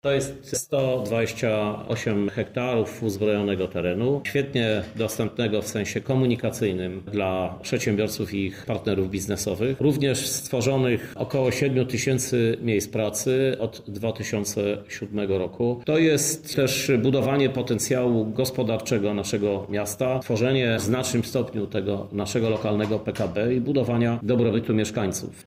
Krzysztof Żuk – mówi Krzysztof Żuk, Prezydent Miasta Lublin.
krzysztof-zuk.mp3